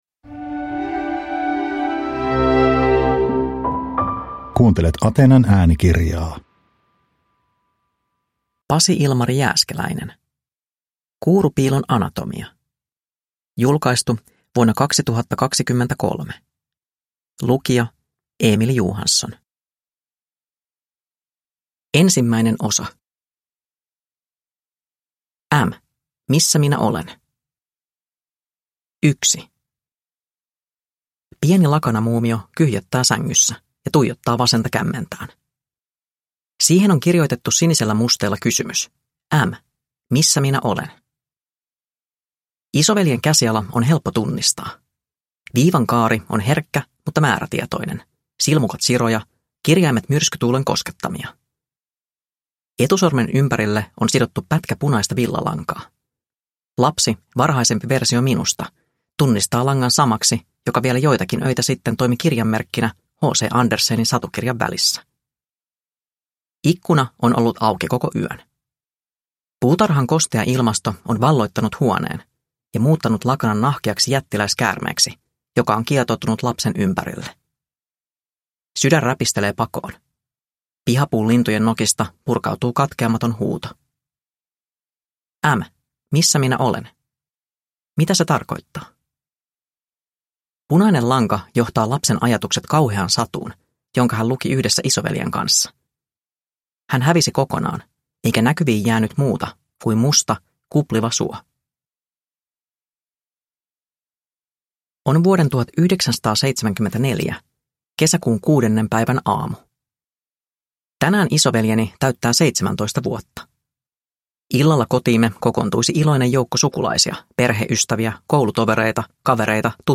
Kuurupiilon anatomia – Ljudbok – Laddas ner